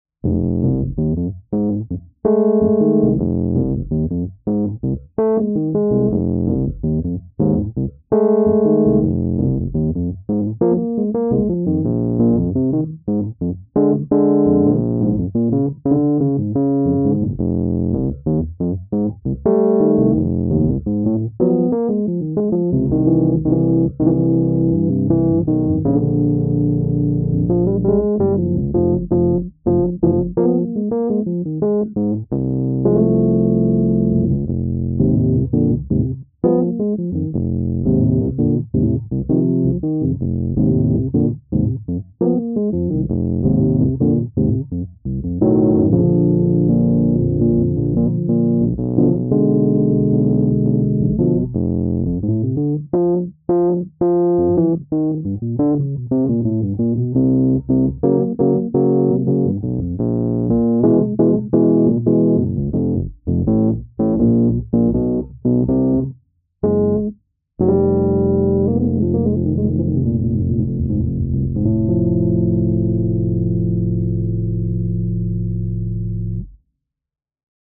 This is a Rhodes Piano Bass from late 1974 with golden tine blocks–one of our very favorite production periods for warm & smooth bass tone.
“Groove Sample:”
74-Fiesta-Piano-Bass-Groove.mp3